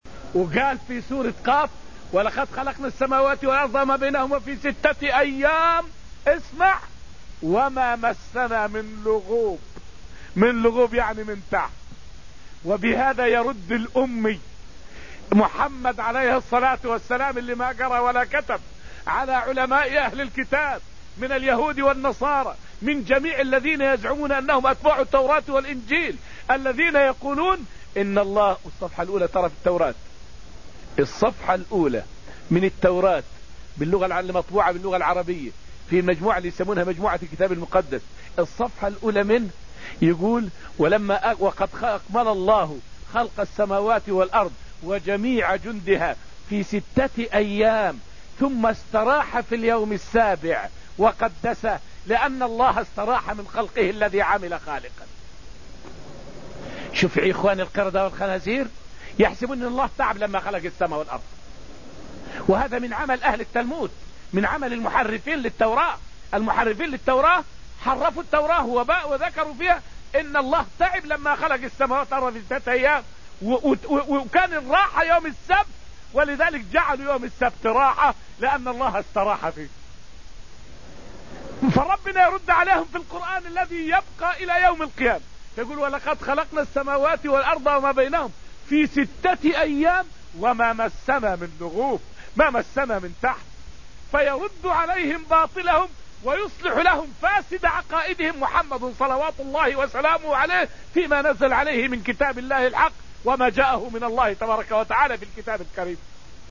فائدة من الدرس الثالث من دروس تفسير سورة الحديد والتي ألقيت في المسجد النبوي الشريف حول الرد على قول اليهود أن الله استراح بعد خلق السماوات.